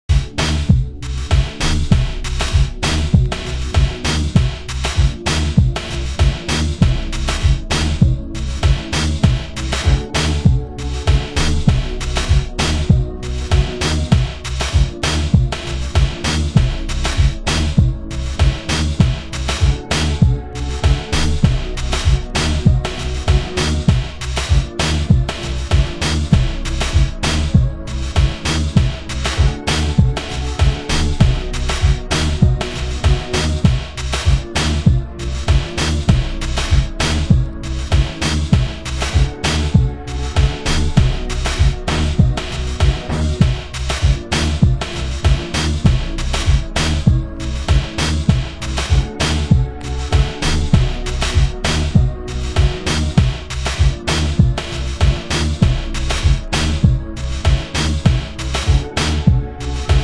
ピッチダウンしたミニマルなマシーンFUNK！